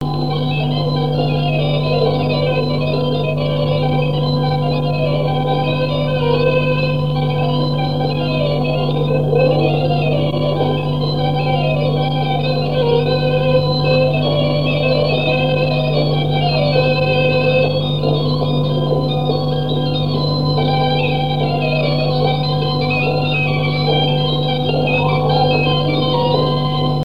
danse : jabadao
Pièce musicale inédite